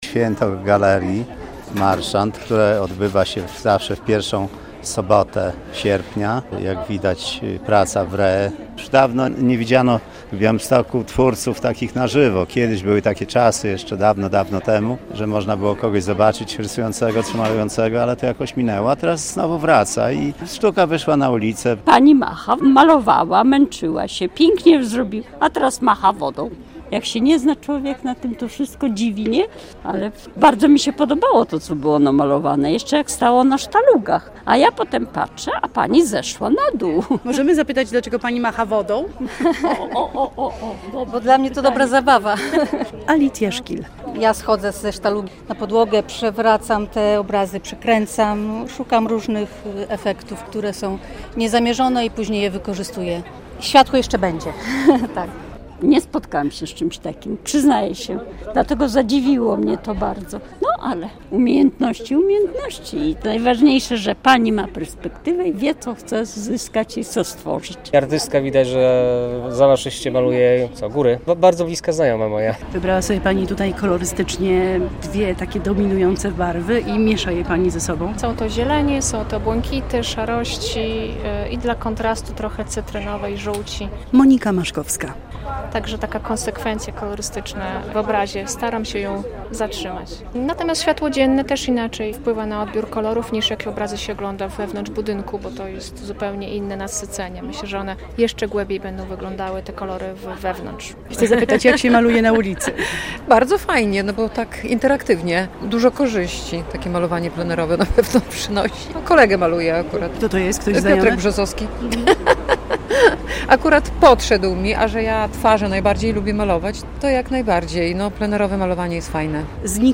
Malowanie na ulicy, warsztaty w siedzibie galerii, a przede wszystkim spotkania i rozmowy - tak co roku świętuje Galeria Marszand przy ulicy Kilińskiego.